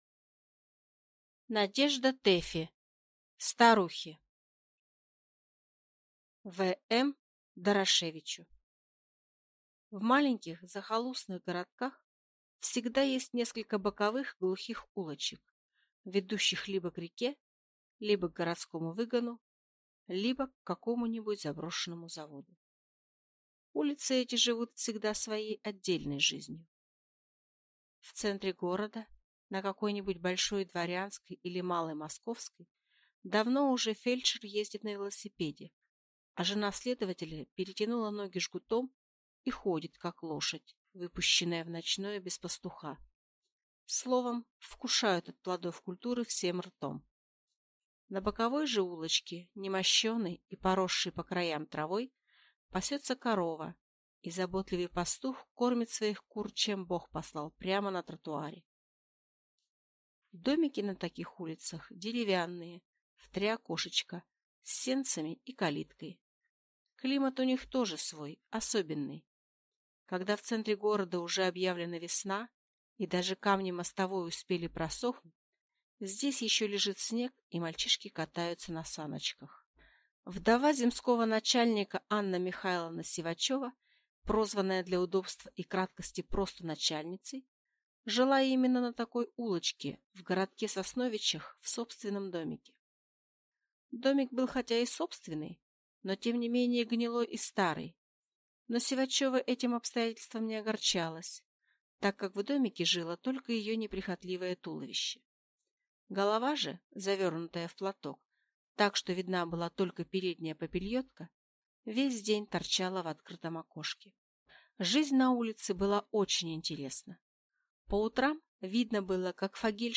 Аудиокнига Старухи | Библиотека аудиокниг